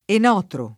Enotro [ en 0 tro ]